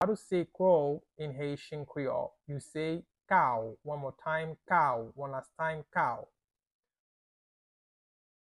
Listen to and watch “Kaw” audio pronunciation in Haitian Creole by a native Haitian  in the video below:
Crow-in-Haitian-Creole-Kaw-pronunciation-by-a-Haitian-teacher.mp3